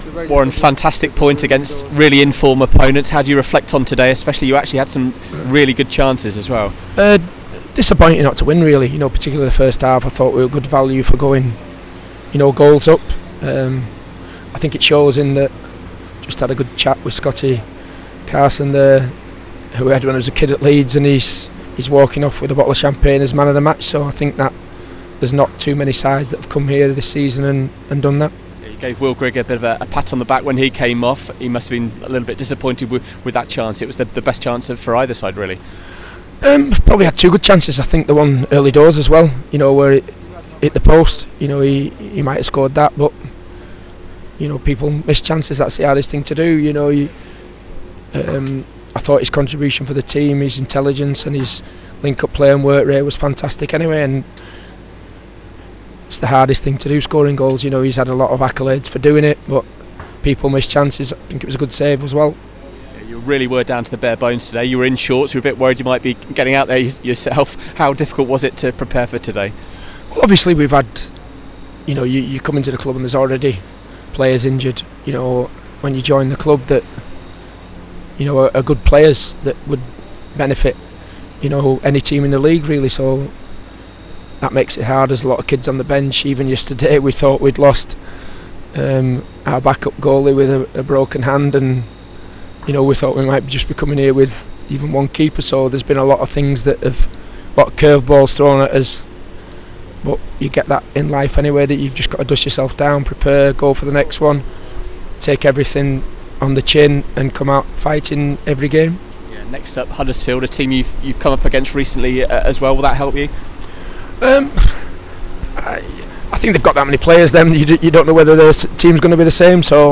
Warren Joyce speaks to the press after his Wigan side were held to a goalless draw at Derby County.